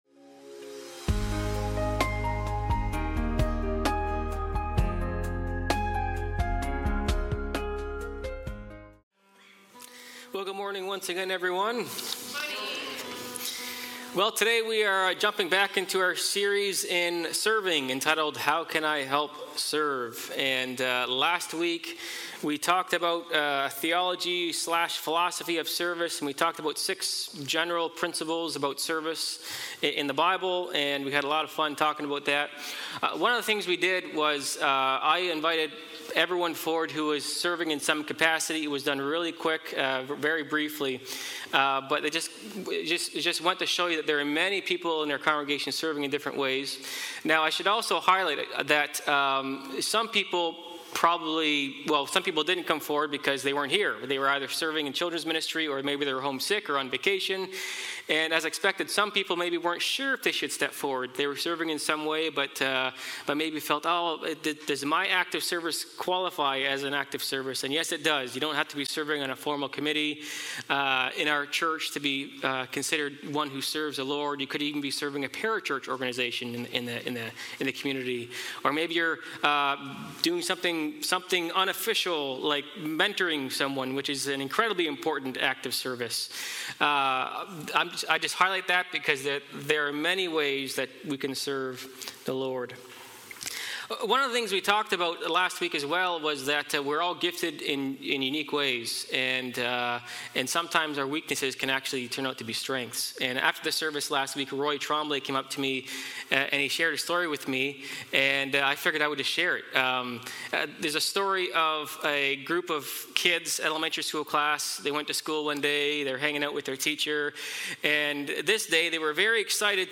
Sermons | Grace Church